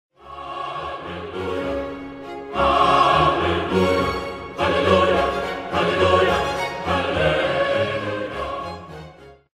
Звук мема «Аллилуйя» в хоровом исполнении